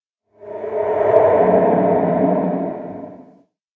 sounds / ambient / cave
cave13.ogg